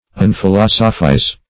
Search Result for " unphilosophize" : The Collaborative International Dictionary of English v.0.48: Unphilosophize \Un`phi*los"o*phize\, v. t. [1st pref. un- + philosophize.] To degrade from the character of a philosopher.